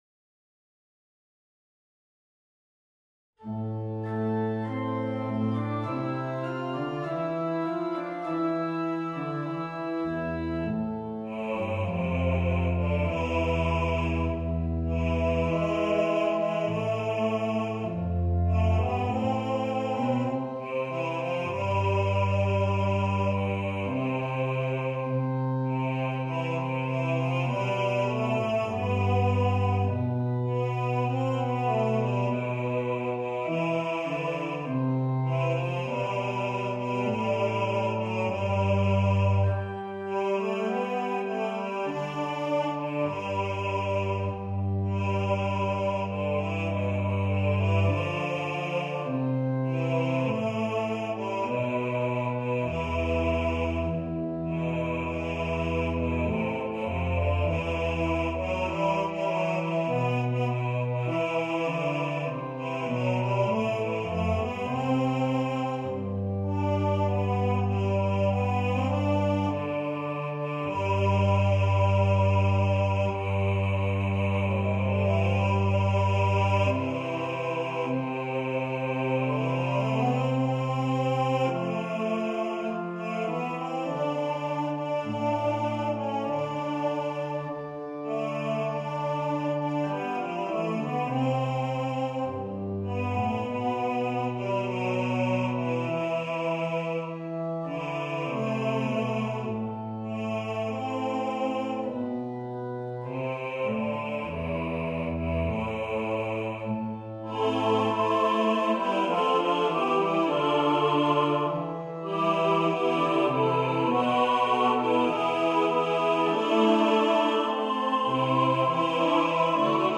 Title: Let God Arise Composer: William Lawes Lyricist: Number of voices: 4vv Voicing: SATB Genre: Sacred, Verse anthem
Language: English Instruments: Basso continuo